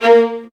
VIOLINS.CN-R.wav